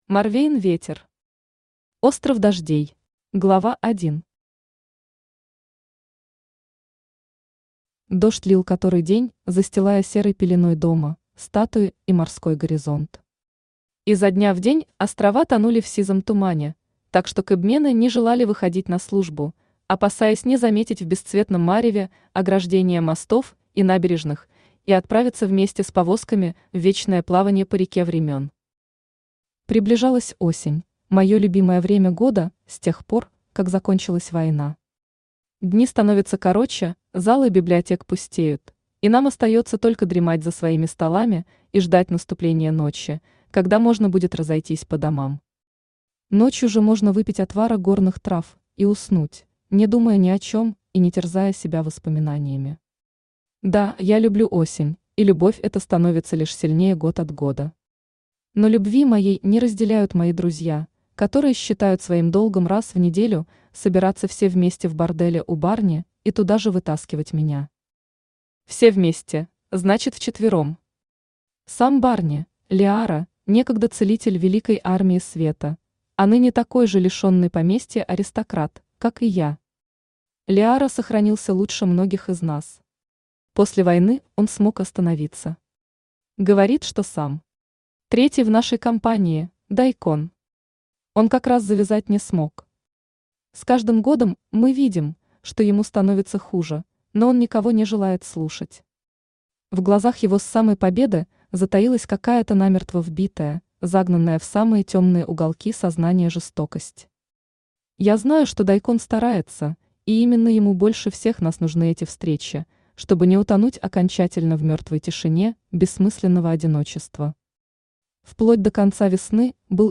Аудиокнига Остров дождей | Библиотека аудиокниг
Aудиокнига Остров дождей Автор Морвейн Ветер Читает аудиокнигу Авточтец ЛитРес.